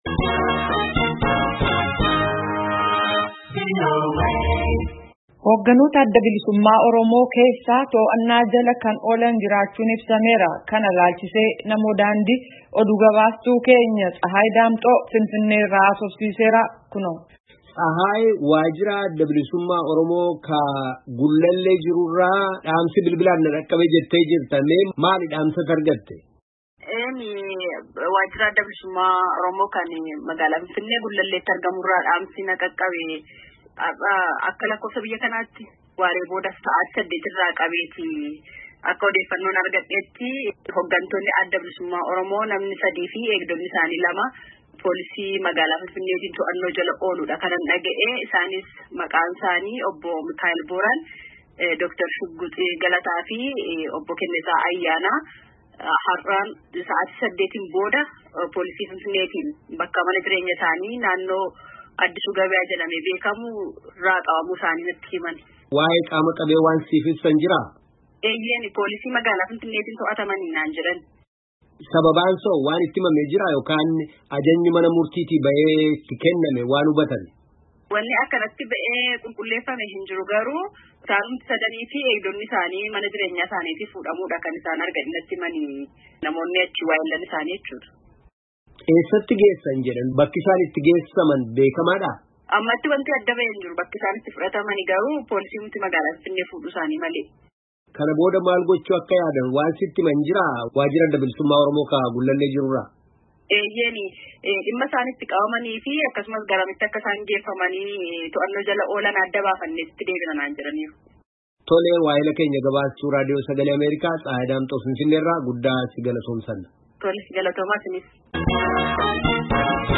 Gaaffii fi deebii gabaastuu VOA